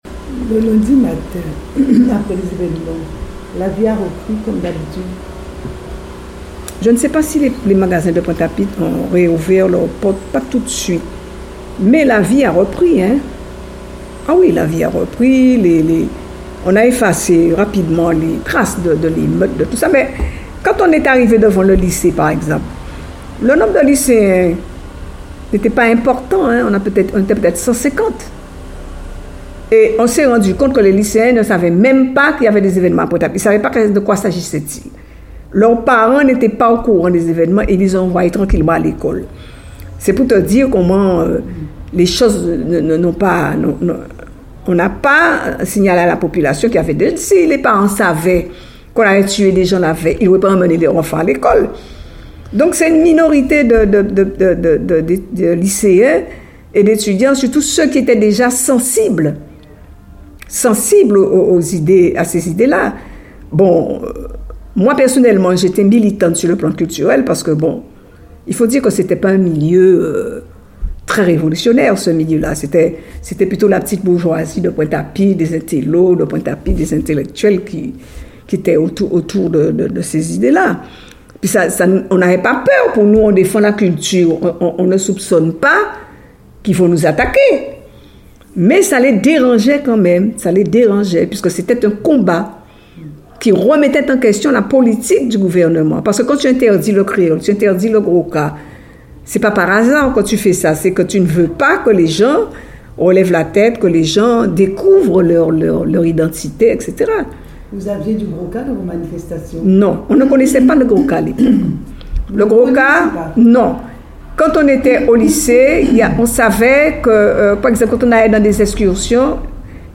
Témoignage sur les évènements de mai 1967 à Pointe-à-Pitre.